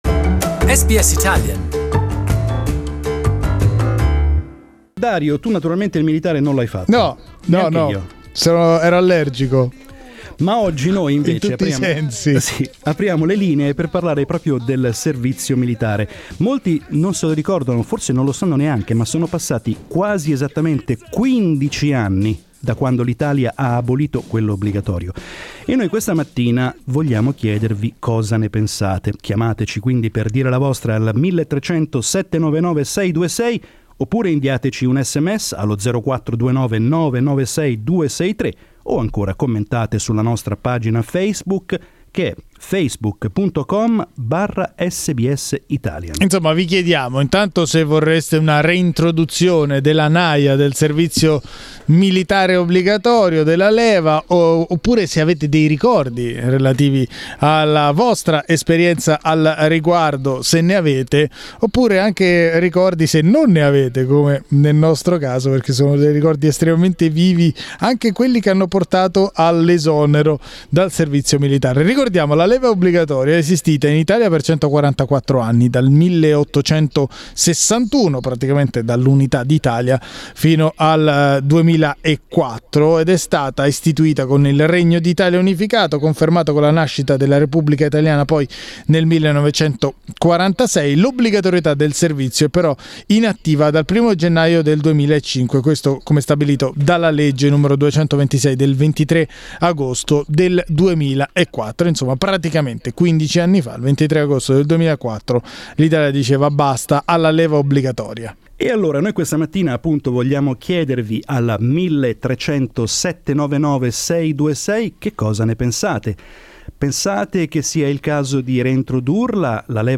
15 years after the end of compulsory military service in Italy, the interior minister Salvini would like to bring it back and today we asked our listeners for their opinion on the matter and also to share their memories of the service.